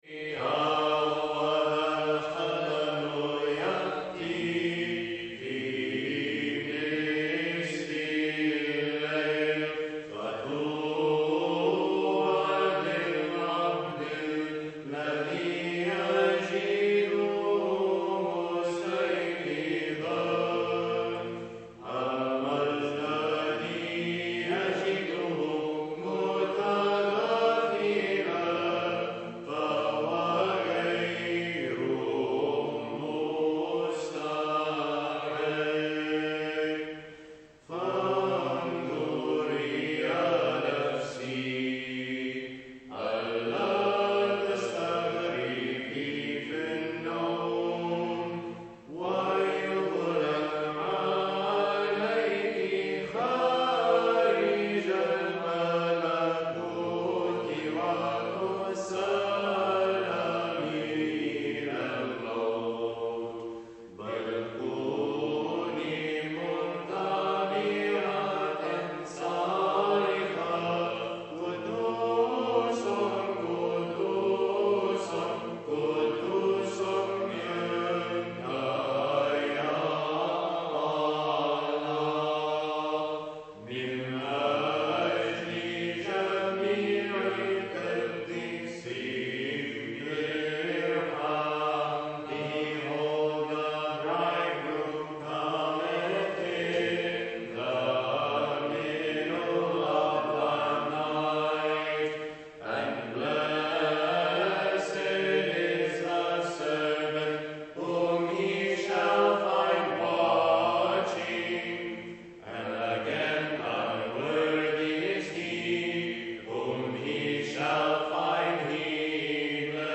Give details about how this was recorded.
Live Recordings from Services